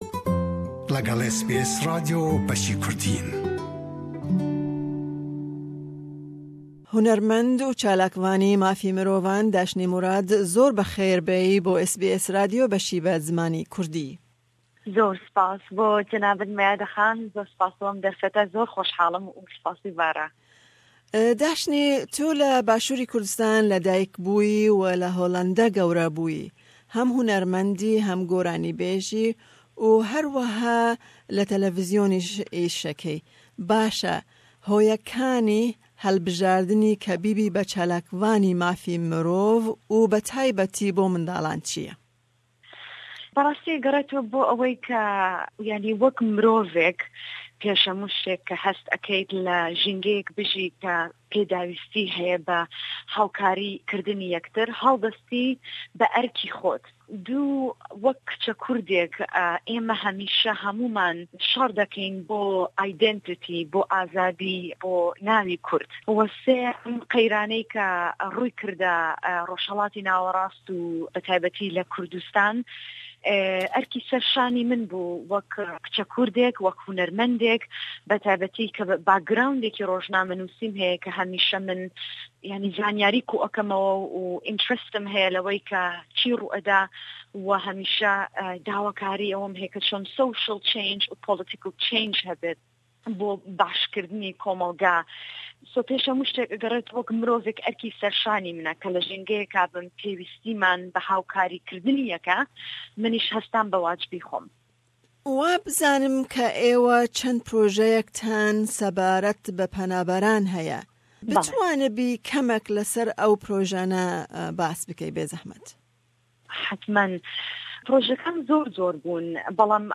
We spoke to Dashni about various issues such as the refugees' situation in Kurdistan Region and the Kurdish woman in today's society.